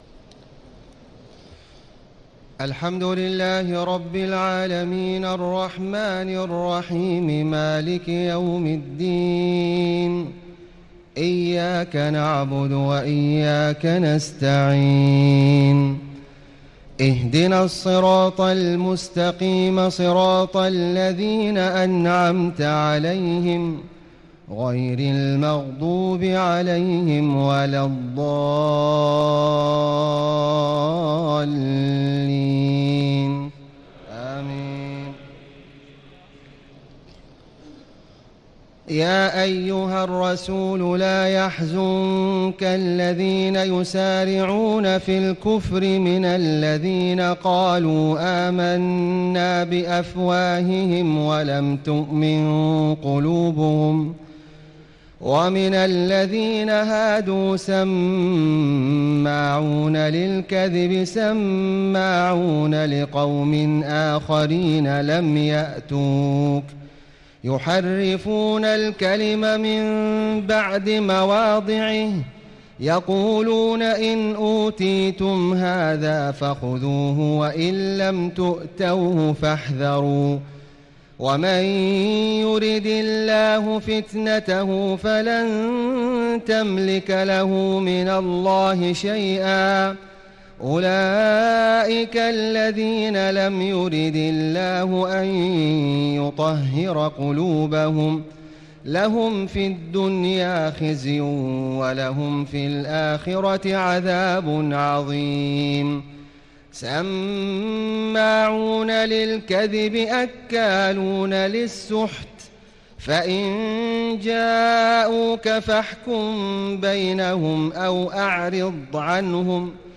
تهجد ليلة 26 رمضان 1436هـ من سورة المائدة (41-81) Tahajjud 26 st night Ramadan 1436H from Surah AlMa'idah > تراويح الحرم المكي عام 1436 🕋 > التراويح - تلاوات الحرمين